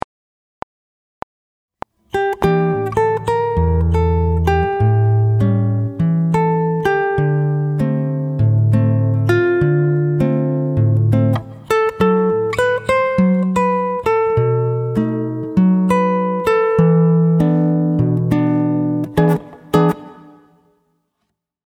Chitarra fingerstyle 16
LUIZ BONFA' Bossa Nova Style